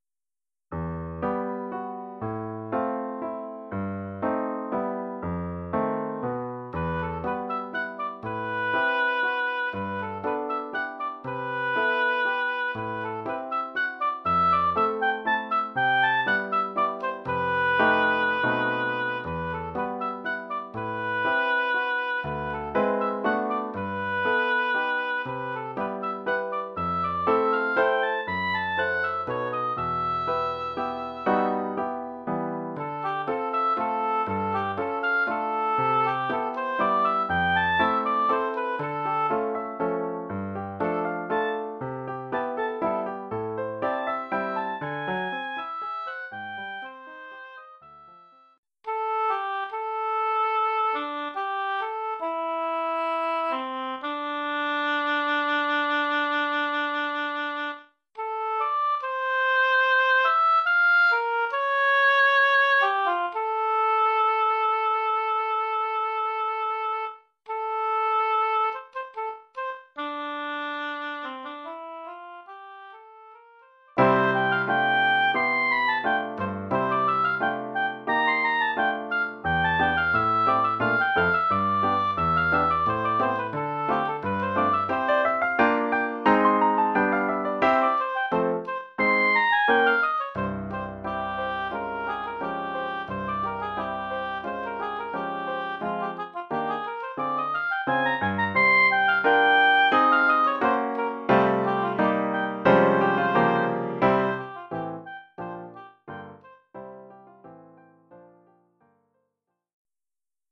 Formule instrumentale : Hautbois et piano
Oeuvre pour hautbois et piano.